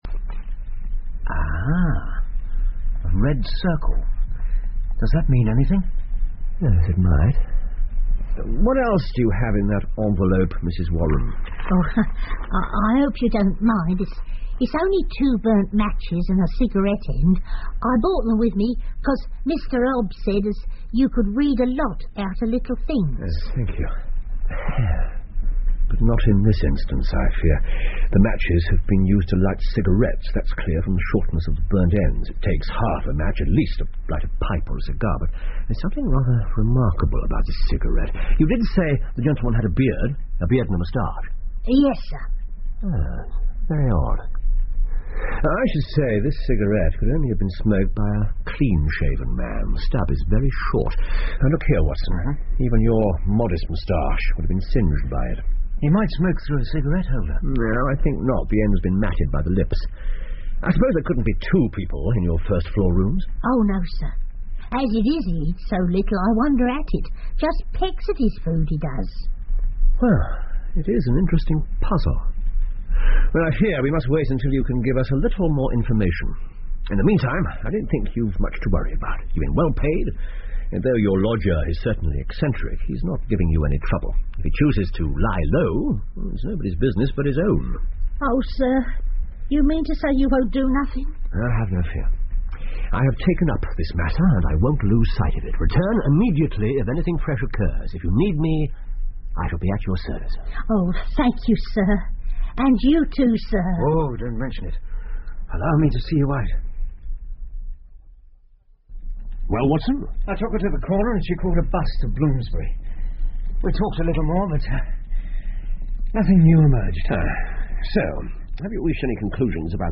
福尔摩斯广播剧 The Red Circle 3 听力文件下载—在线英语听力室